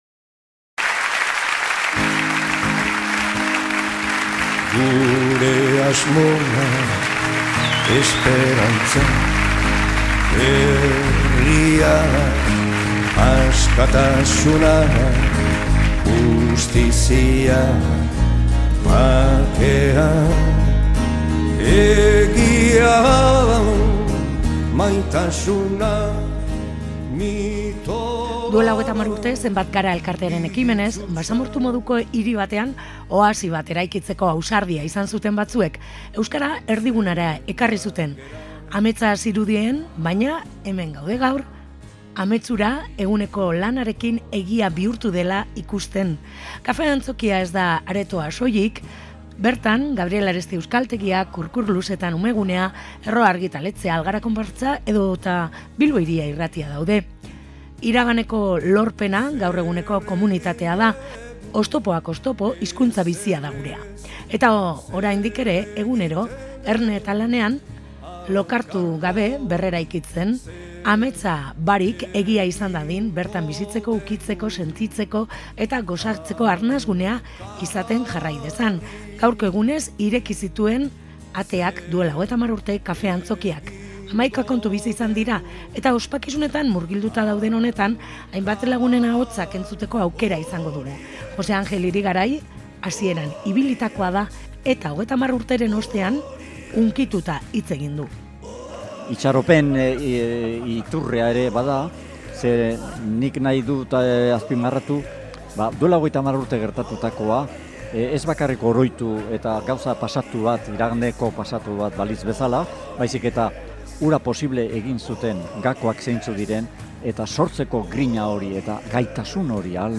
Gaurko egunez ireki zituen ateak duela 30 urte kafe Antzokiak, hamaika kontu bizi izan dira eta ospakizunetan murgilduta duden honetan hainbat lagunen ahotsak entzuteko aukera izan dugu.